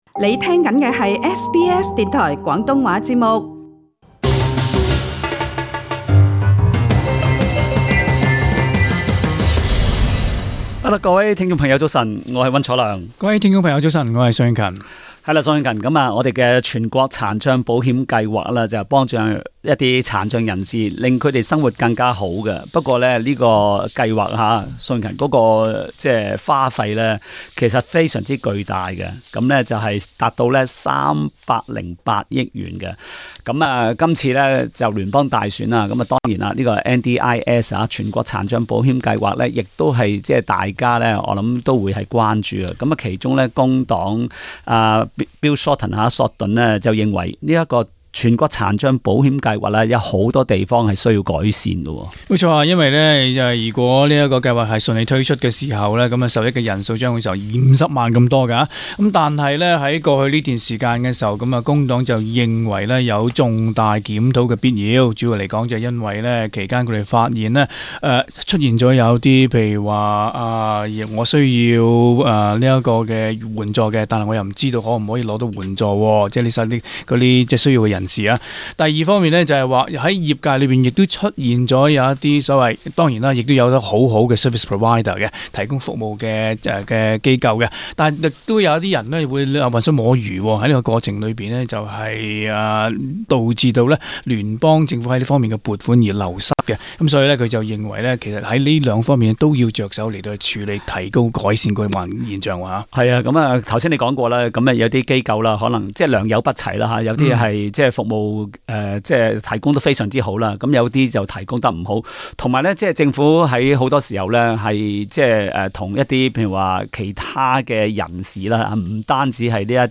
Source: AAP SBS廣東話節目 View Podcast Series Follow and Subscribe Apple Podcasts YouTube Spotify Download (4.69MB) Download the SBS Audio app Available on iOS and Android 聯邦工黨不滿全國殘障計劃現狀，計劃一旦上台便將它徹底改革。